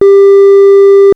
FIGURE 1.14. Variation of pitch.